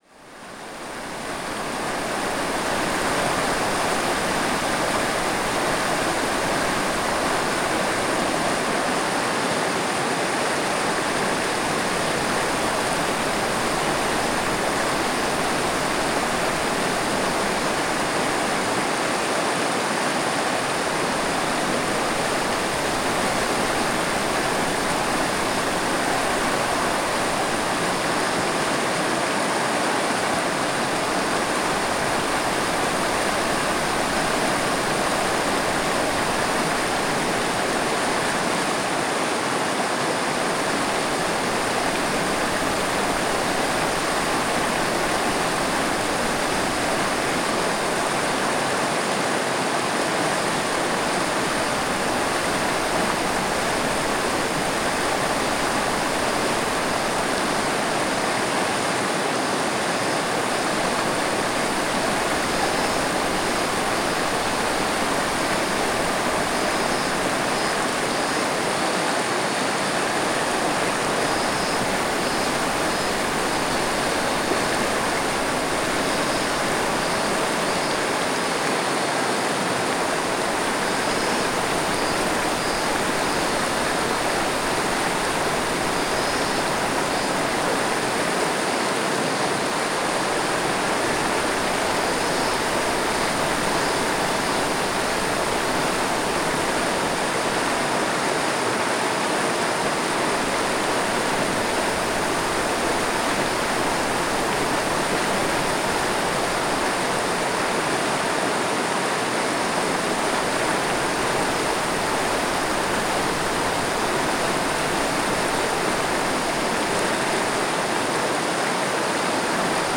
三段峡528流れ.wav